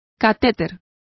Complete with pronunciation of the translation of catheter.